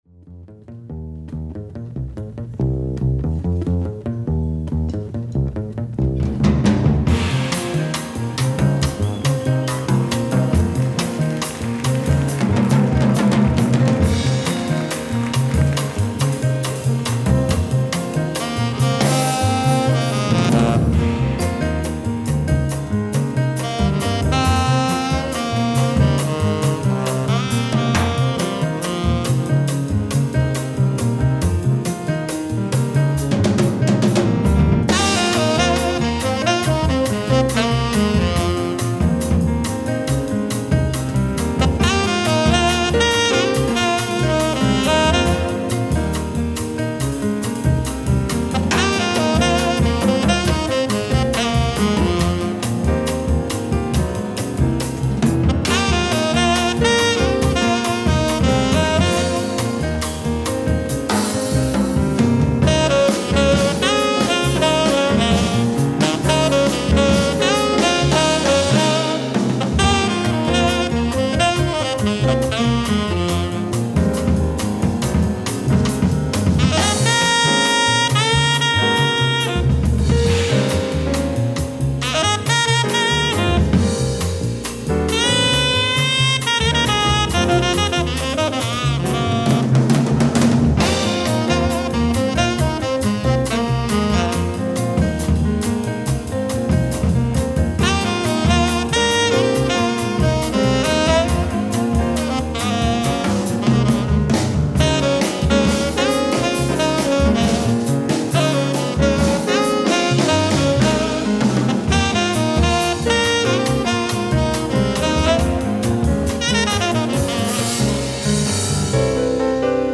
ライブ・アット・レバークーゼン、ドイツ 10/19/1998
※試聴用に実際より音質を落としています。